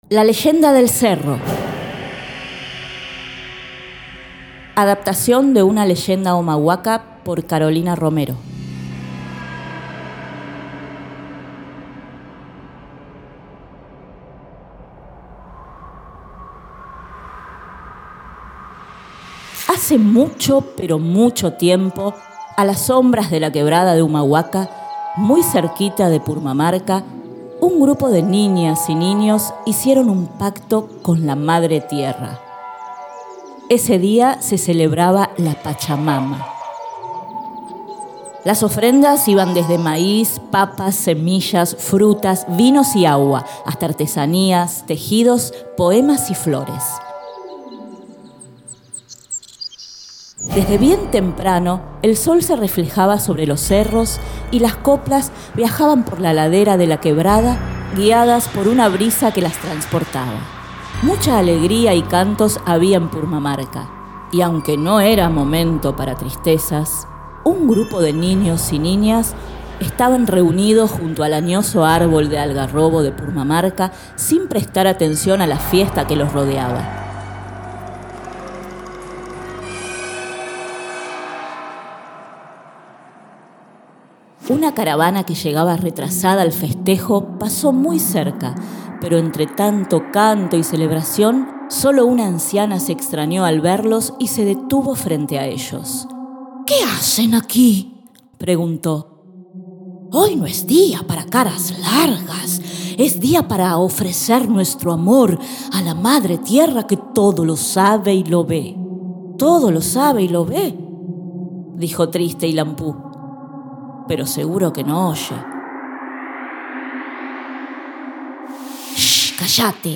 Cuentos contados